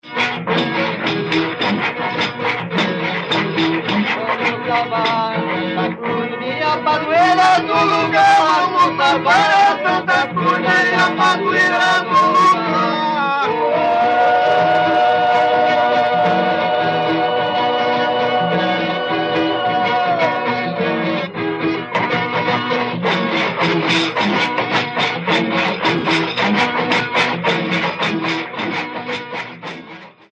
Dança de pares, com formação em círculo, que compreende três partes: Saudação, Roda e Despedida. A Saudação e a Despedida são consideradas sagradas, apresentam melodias que lembram a seqüência sonora dos cantos gregorianos, versos fixos e temática devocional, e são executadas diante do cruzeiro.
Na Roda, parte profana da dança, as melodias têm versos tradicionais ou circunstanciais, com texto amoroso e jocoso.
Ocorre durante a Festa da Santa Cruz.